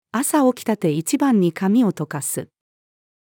朝起きたて一番に髪をとかす。-female.mp3